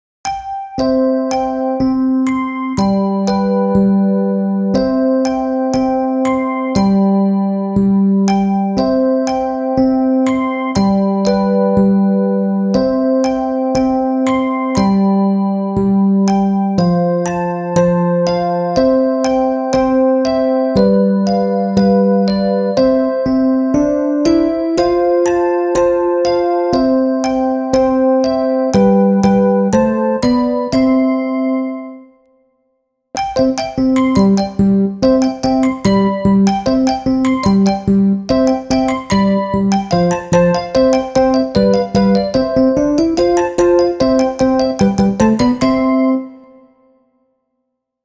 klein_duimpje_langzaamensnelmelodietje_voorbeeld.mp3